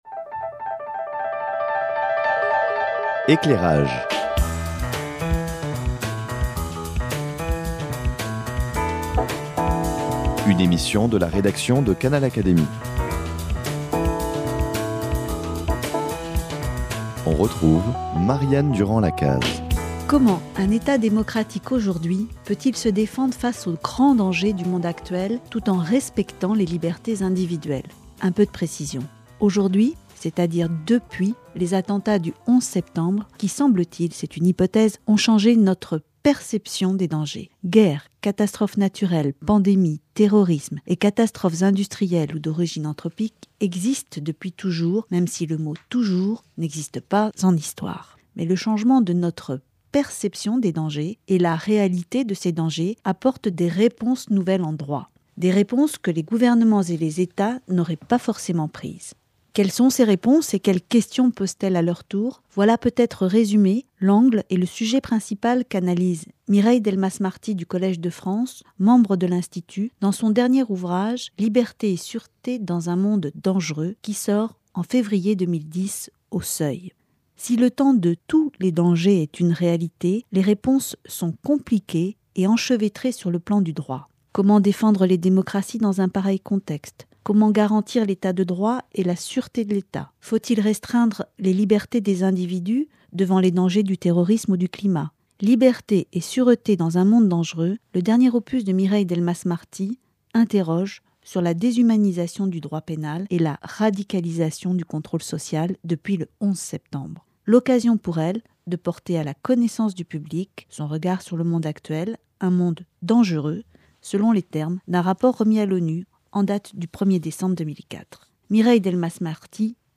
Libertés et sûreté dans un monde dangereux : entretien avec Mireille Delmas-Marty